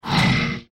File:Mutant desert rat roar.mp3
Mutant_desert_rat_roar.mp3